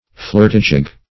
Flirtigig \Flirt"i*gig\, n. A wanton, pert girl.